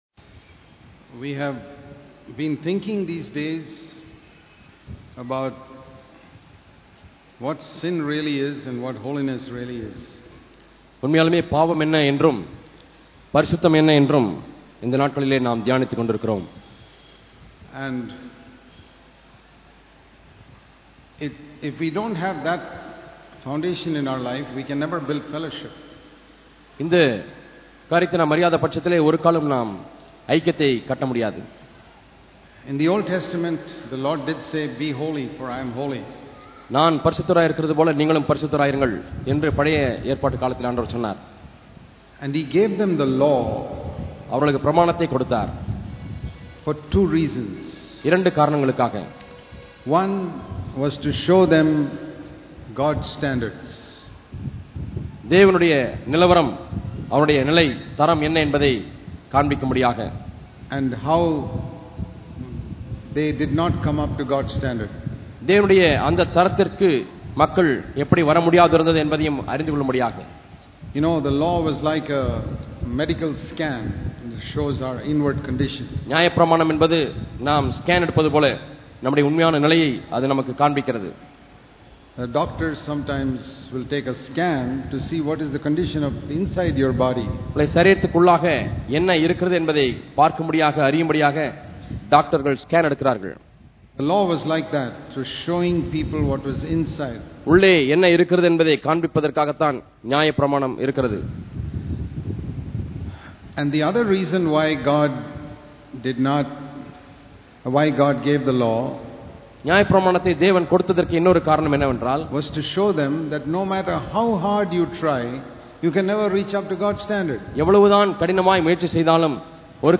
Inward Holiness And Building The Body Holiness and Fellowship Click here to View All Sermons இத்தொடரின் செய்திகள் நான் பாவத்தின் கொடுமையை பார்த்துள்ளேனா?